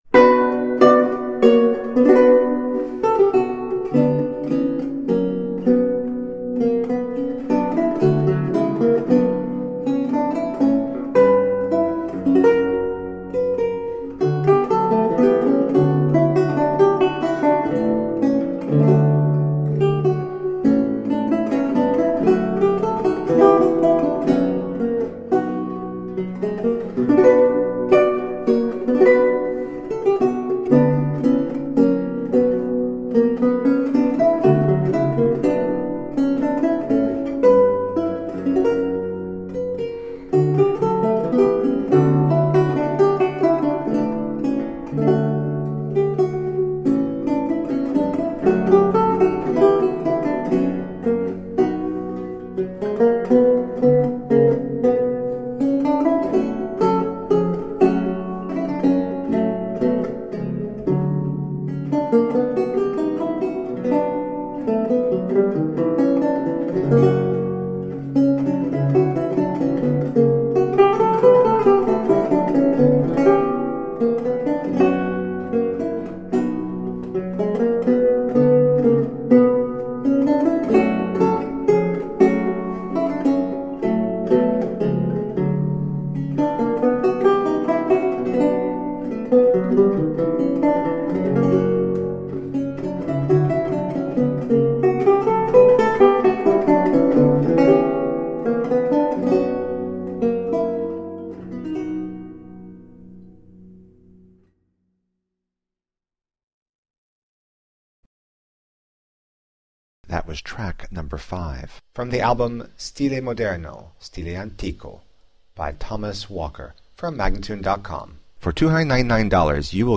Lute music of 17th century france and italy.